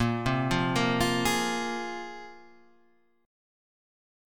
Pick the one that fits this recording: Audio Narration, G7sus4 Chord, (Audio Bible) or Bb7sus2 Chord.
Bb7sus2 Chord